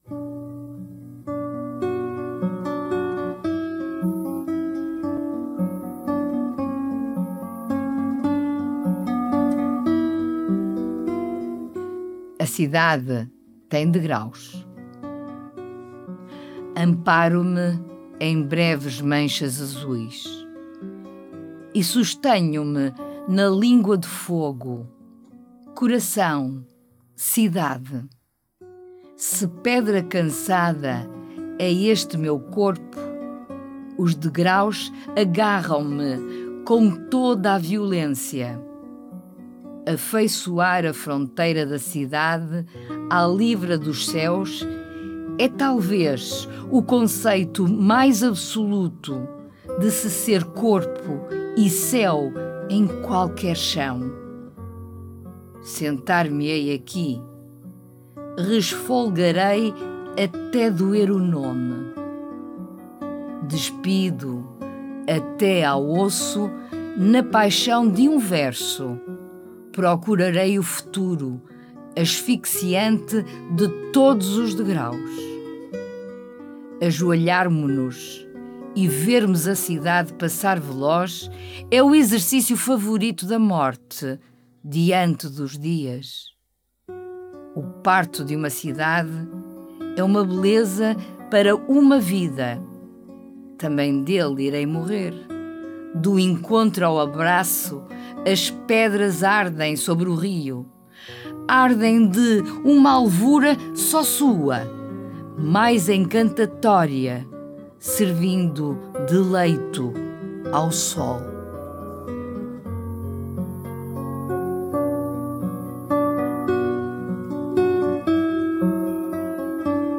Música: Étude in B minor (op. 35, nº 22) de Fernando Sor